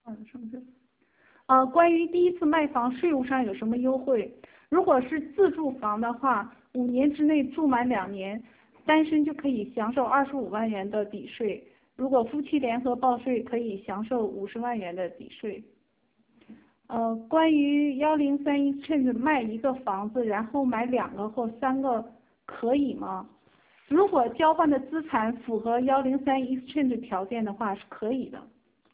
这里是上周日（3/8），亚特兰大【房群】举办的微信讲座问答汇集（二）；问题是文字形式，对答是语音形式，点击即可播放。